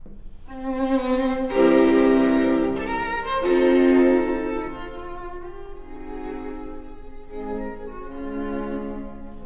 ↑古い録音のため聴きづらいかもしれません！（以下同様）
終楽章は、変奏曲。
主題と10の変奏で構成されます。
下2声が独特のリズムを作り、1stVnが優雅に歌い上げます。
バイオリン2本、ビオラ1本という、この曲でしか味わえない感覚のある曲です。